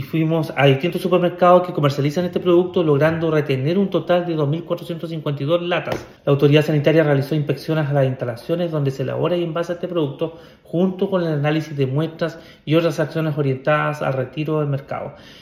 El seremi de Salud regional, Andrés Cuyul, señaló a Radio Bío Bío que equipos fiscalizadores recorrieron distintos supermercados logrando retener un total de 2.452 latas para impedir su venta y consumo.